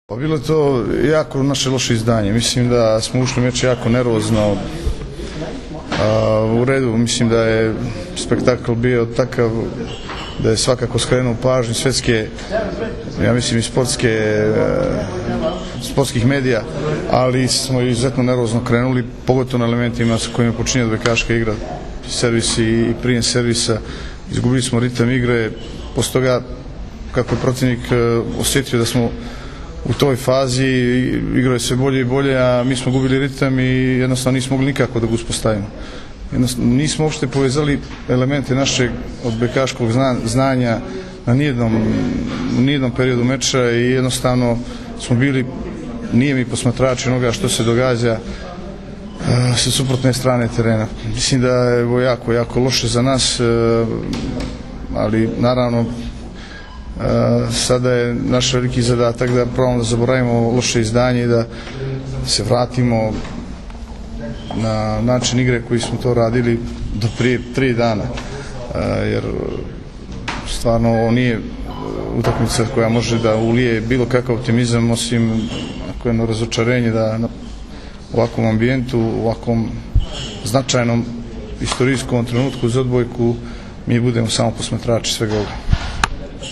IZJAVA IGORA KOLAKOVIĆA